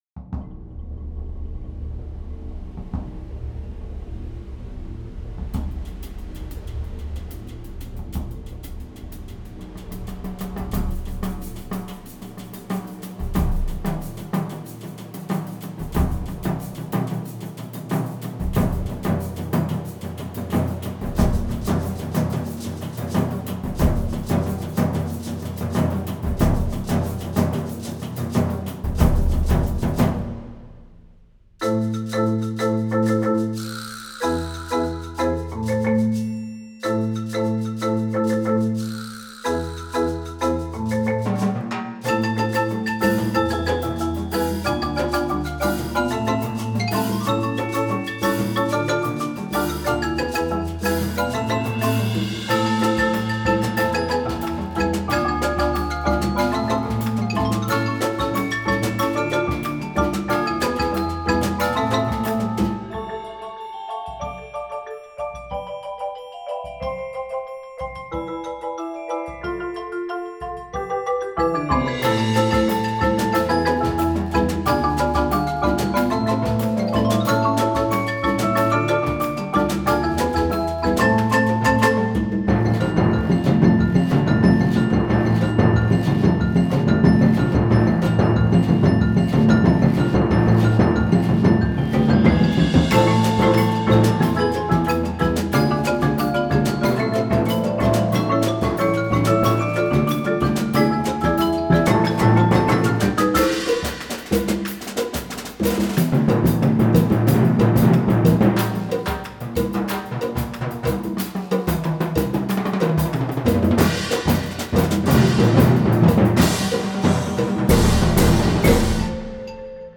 Voicing: 10-14 Percussion